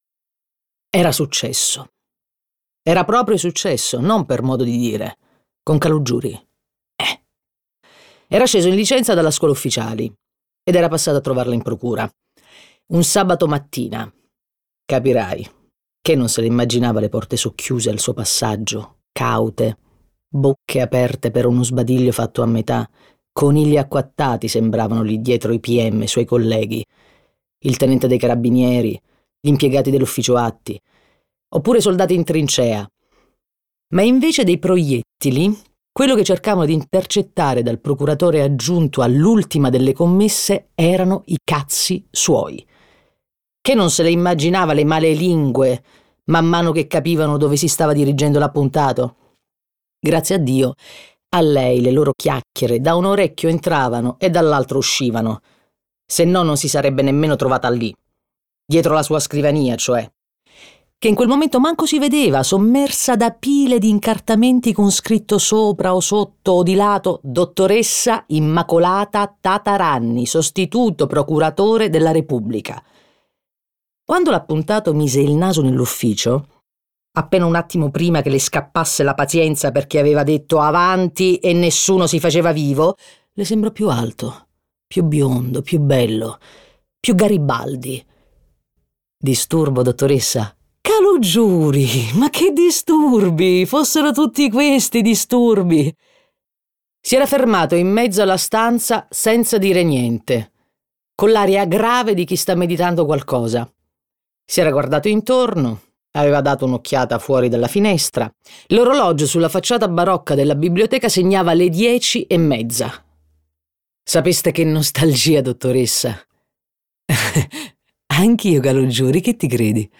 letto da Vanessa Scalera
Versione audiolibro integrale